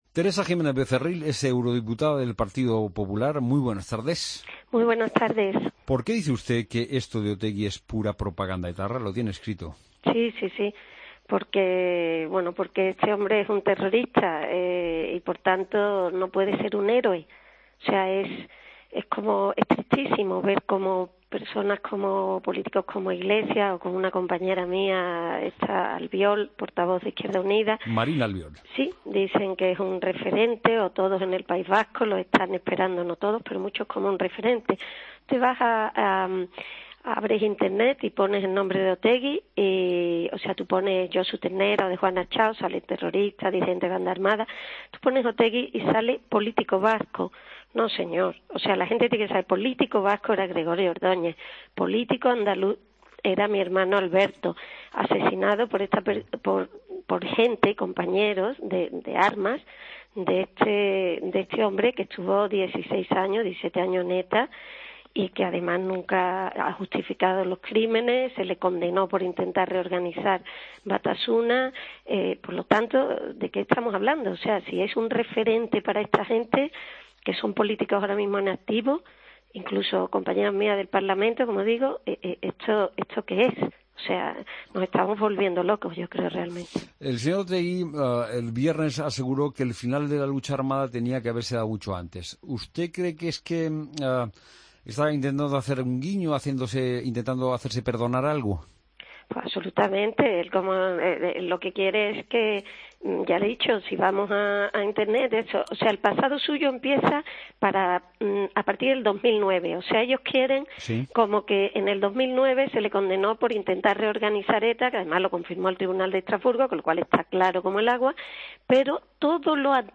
Escucha la entrevista a Teresa Jiménez-Becerril, Eurodiputada del PP, en Mediodía COPE.